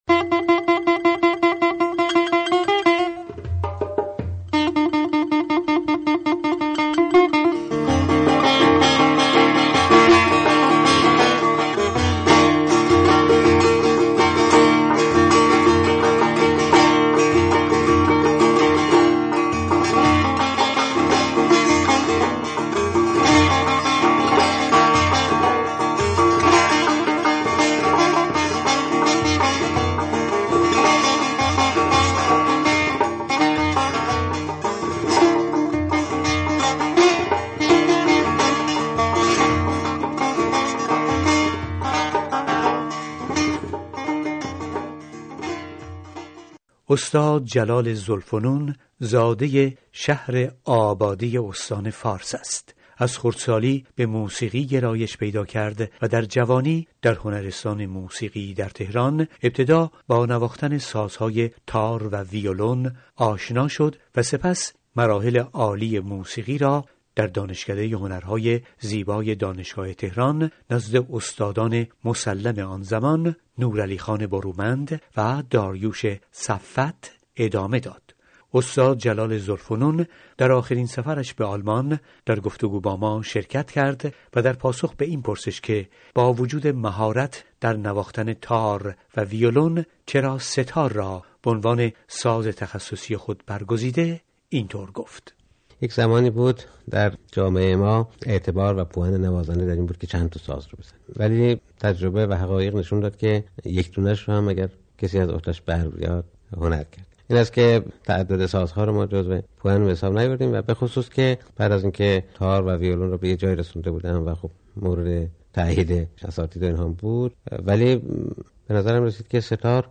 مصاحبه با وی